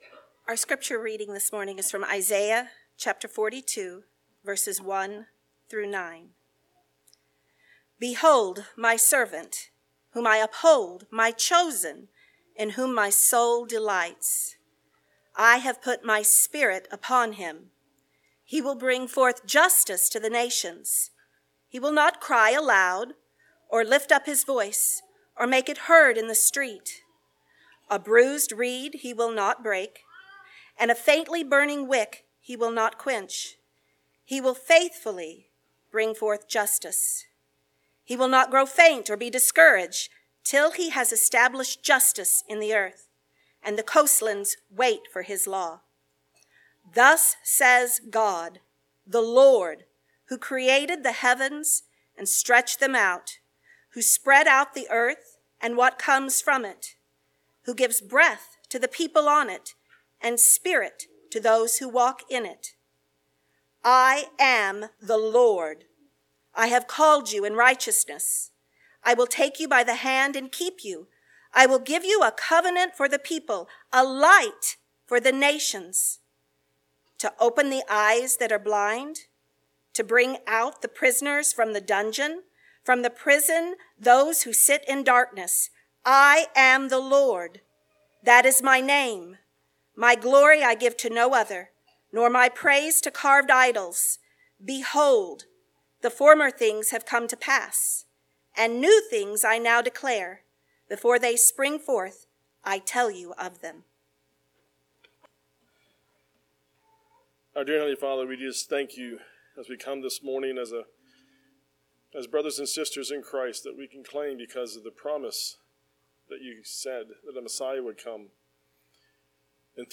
Advent 2024 Passage: Isaiah 42:1-9 Service Type: Sunday Morning Related Topics